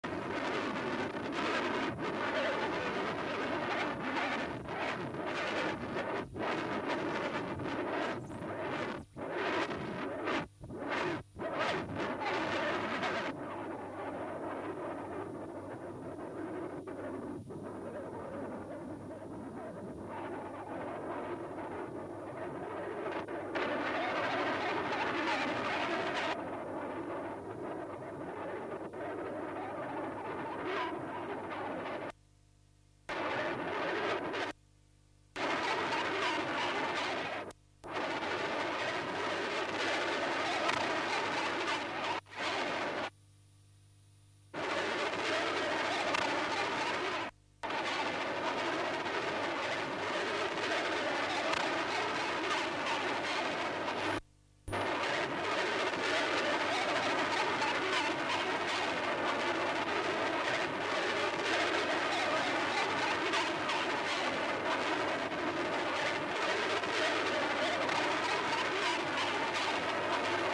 可能、 しかしの、生音しか聞えないからどぅ録音されてんのか判らず再生音は薄ッ気味悪いギターの音色 ワゥフラッター